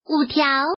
Index of /mahjong_gansu_test/update/1686/res/sfx/woman/